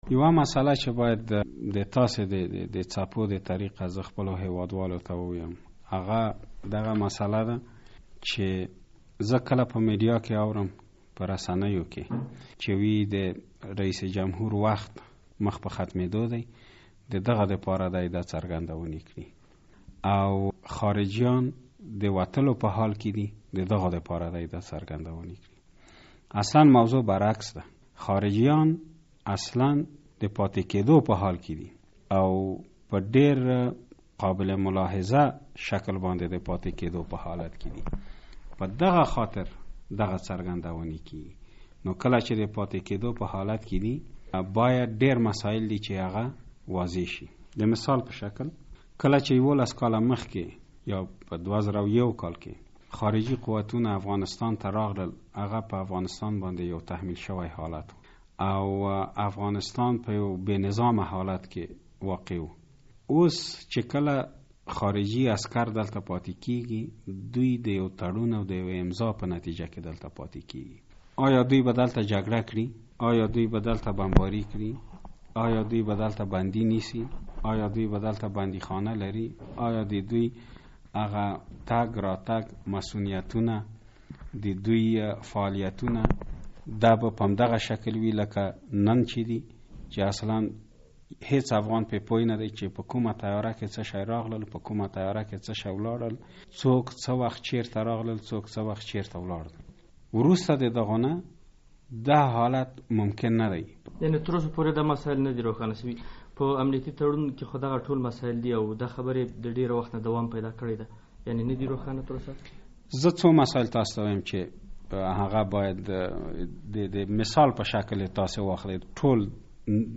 له عبدالکریم خرم سره مرکه